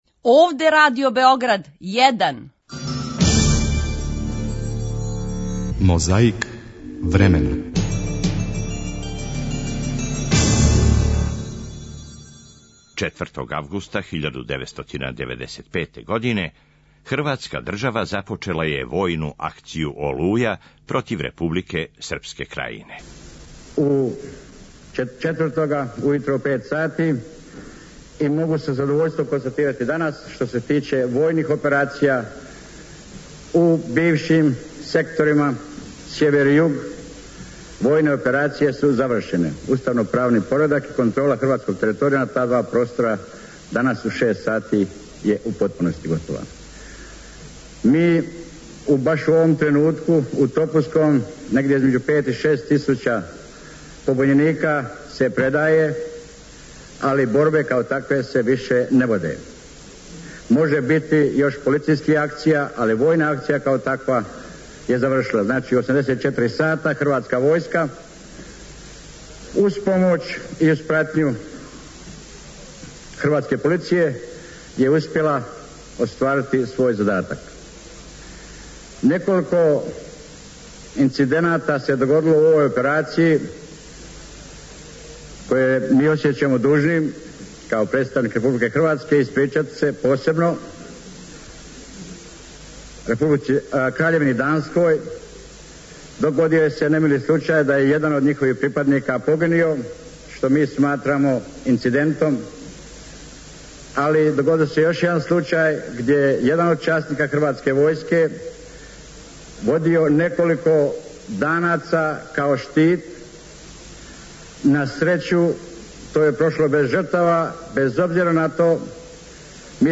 Подсећамо вас како су говорила двојица председника.
Чућете извештаје медија, политичких функционера и грађана из колоне.